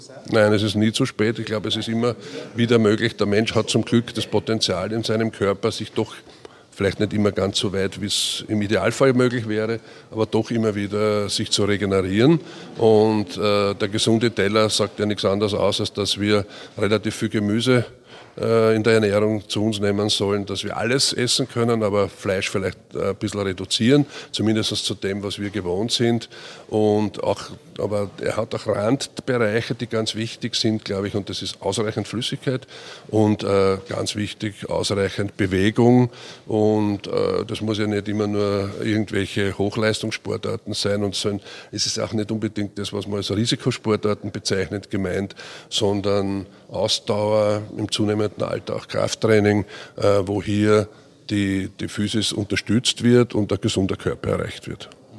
O-Töne (mp3)